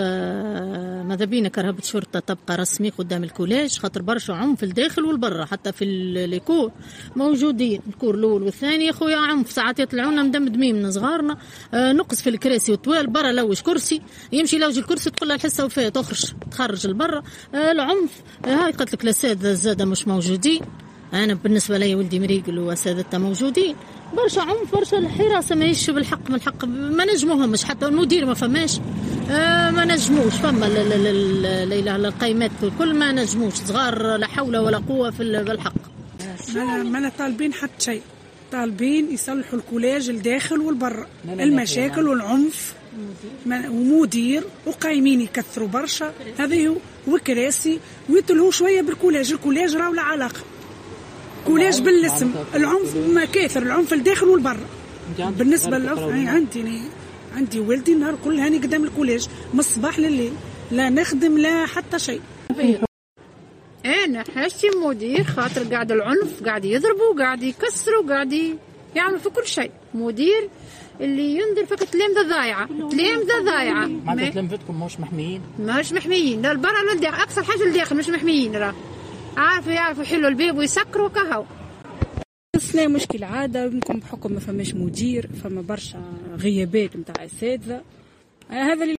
Dans une déclaration accordée au micro de Tunisie Numérique, les parents d’élèves du collège de Grombalia, relevant du gouvernorat de Nabeul, ont exprimé leur exaspération face à la propagation de la violence à l’intérieur et à l’extérieur de l’établissement éducatif.